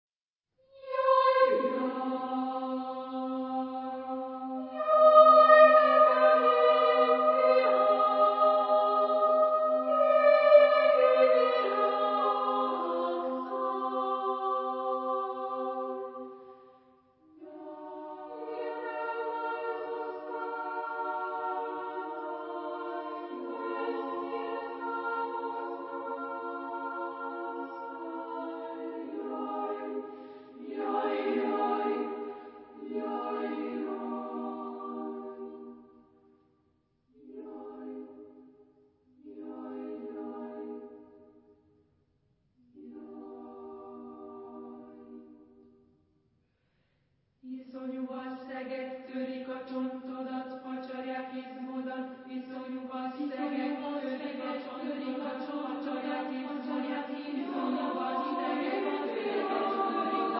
Género/Estilo/Forma: Canto fúnebre ; Coro
Tipo de formación coral: SMA  (3 voces Coro femenino )
Tonalidad : sistemas tonales con variaciones modales